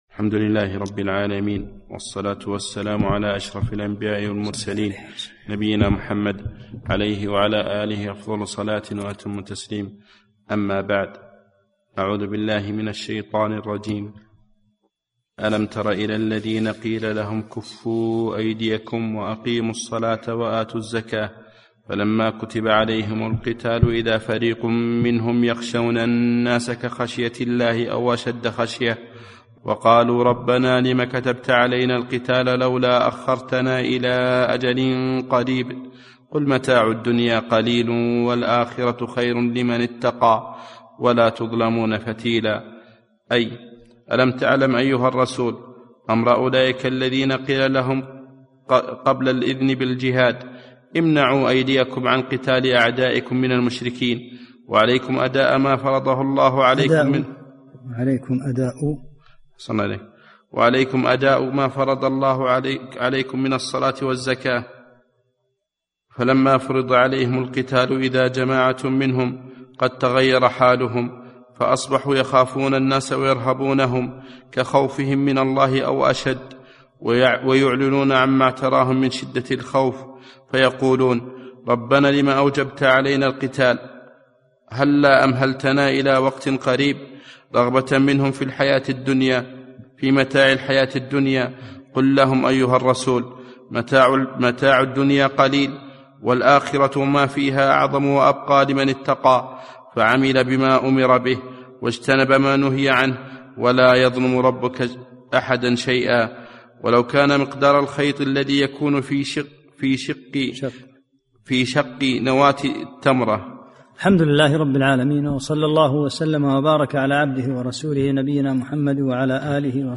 10 - الدرس العاشر